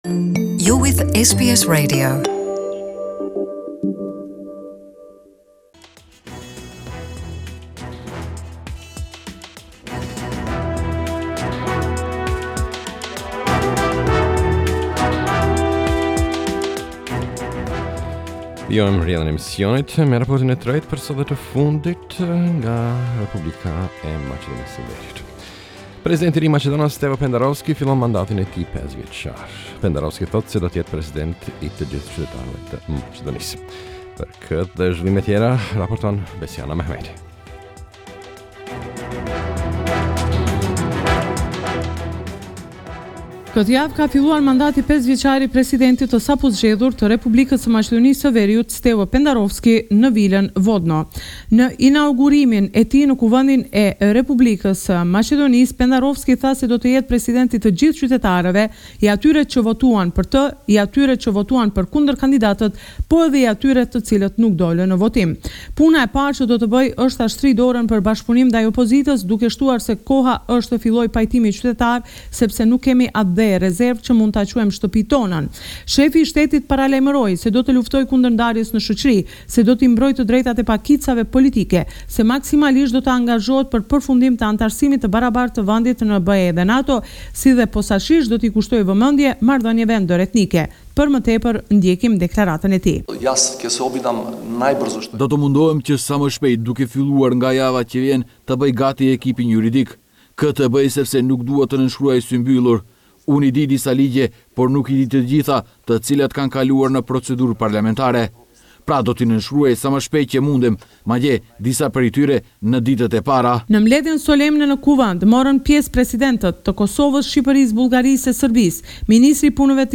This is a report summarising the latest developments in news and current affairs in Macedonia